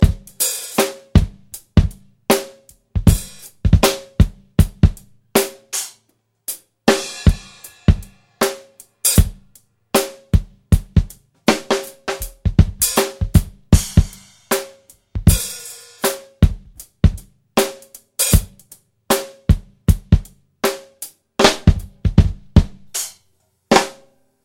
Drum Loops
Funk Shuffle (extrait de solo)
Swing / 78,528 / 8 mes